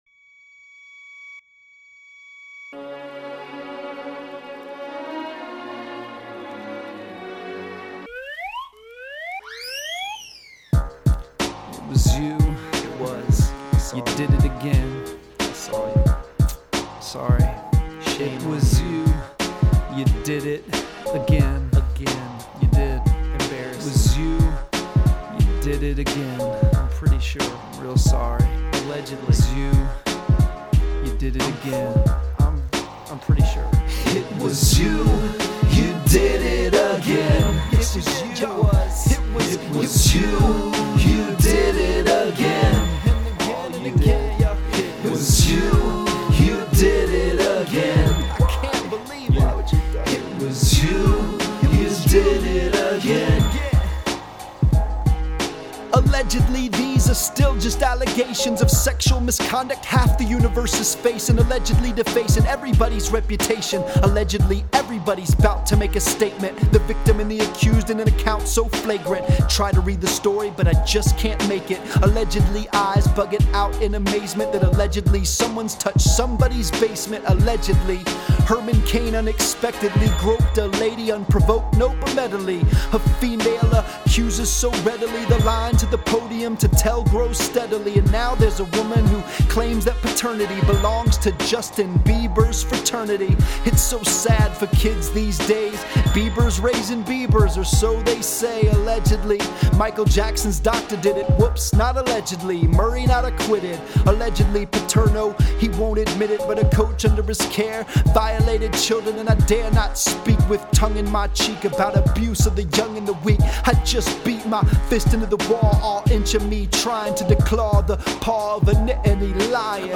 Please don’t mistake the whimsy and snide in today’s song for some sort of flippancy or disdain for the accusser or their allegations.